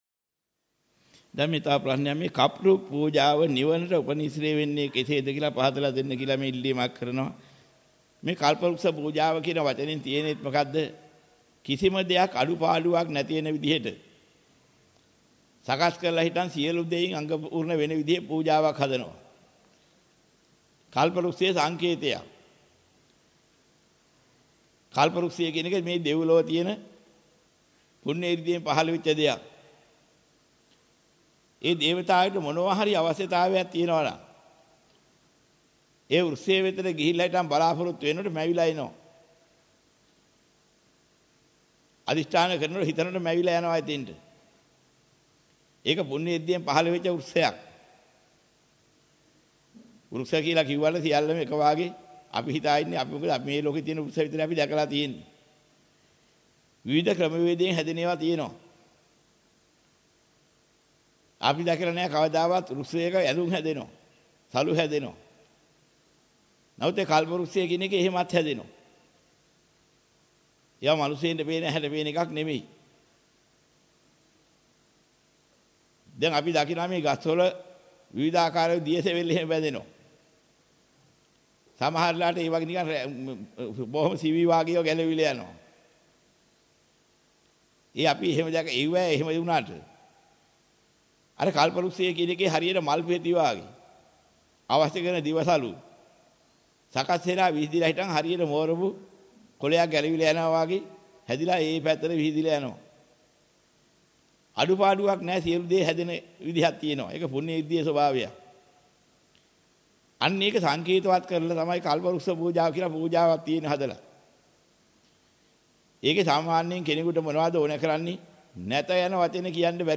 වෙනත් බ්‍රව්සරයක් භාවිතා කරන්නැයි යෝජනා කර සිටිමු 09:20 10 fast_rewind 10 fast_forward share බෙදාගන්න මෙම දේශනය පසුව සවන් දීමට අවැසි නම් මෙතැනින් බාගත කරන්න  (5 MB)